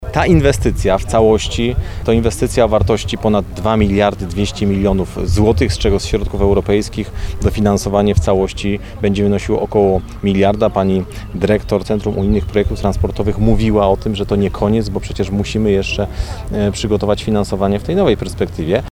W Dankowicach, w sąsiedztwie placu budowy odcinka drogi S1, odbyła się konferencja prasowa.
– Systematycznie budujemy kolejne drogi, aby Polska rozwijała się równomiernie w każdym swoim zakątku.